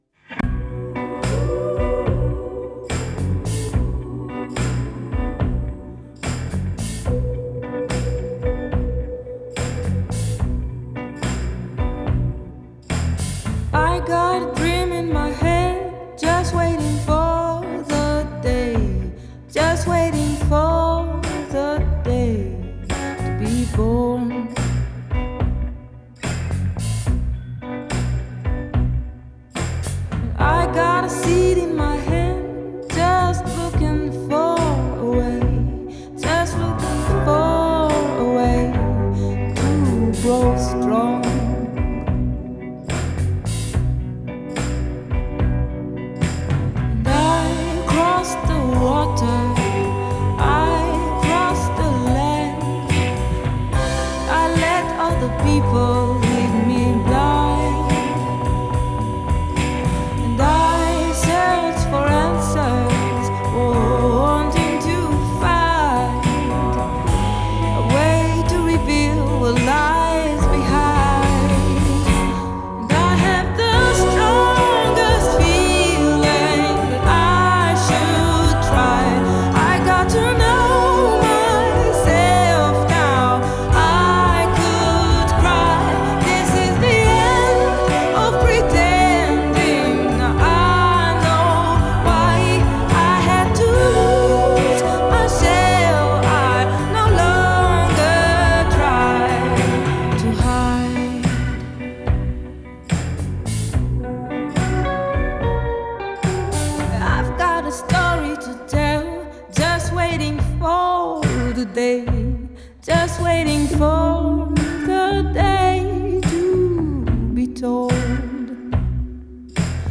Жанр: Indie, Folk, Pop
Genre: Female vocalists, Indie, Folk, Pop